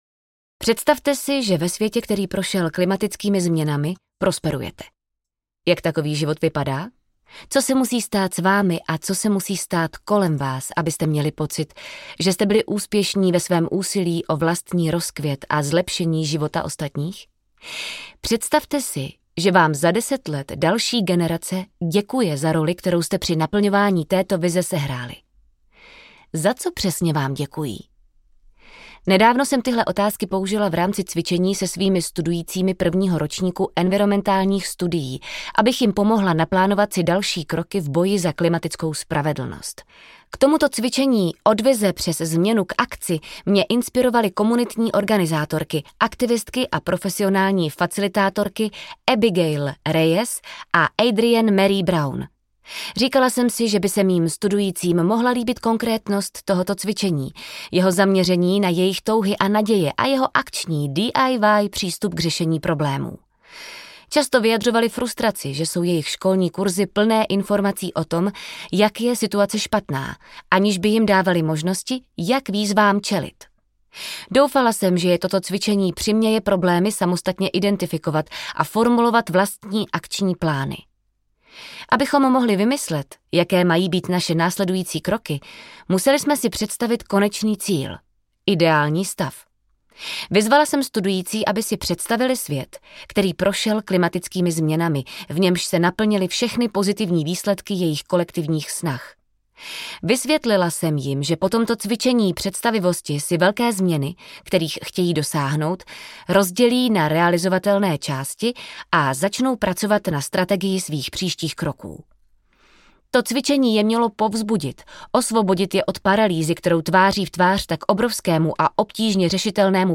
Průvodce klimatickou úzkostí audiokniha
Ukázka z knihy
Vyrobilo studio Soundguru.